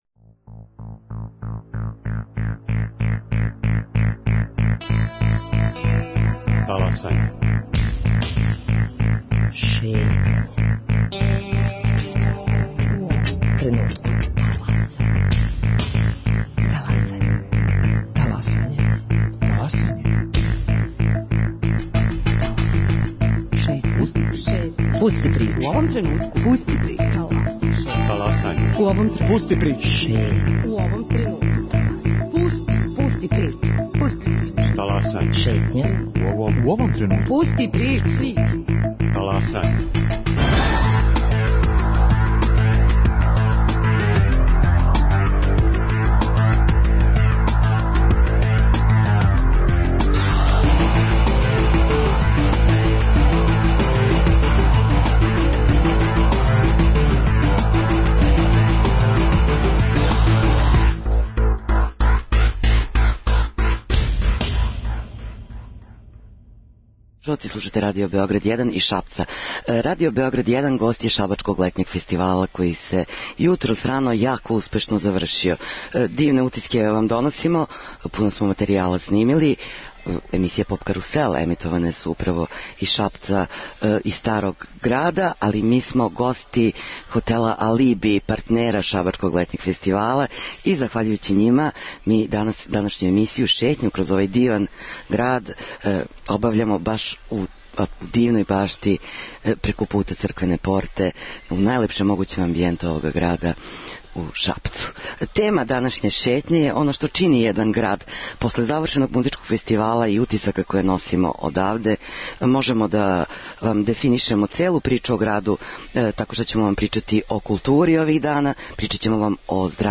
Емисија се емитује из Шапца, где је у току Шабачки летњи фестивал.